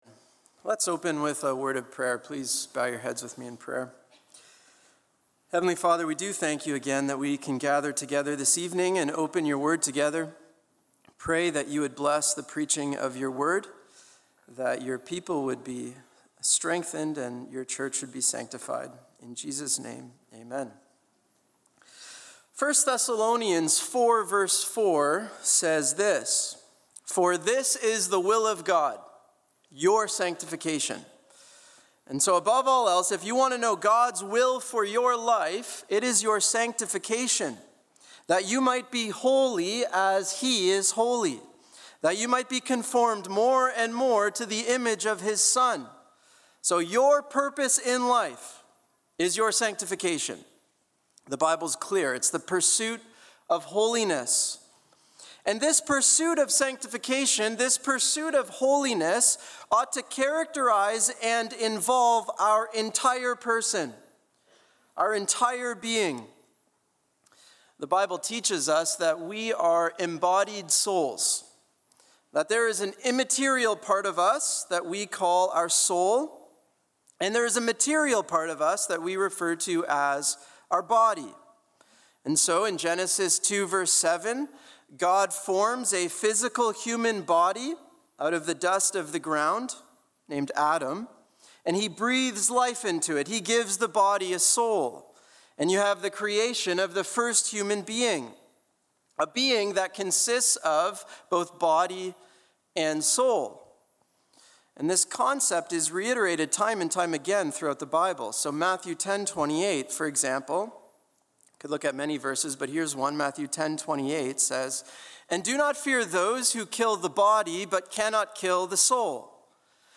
Single Sermons Topics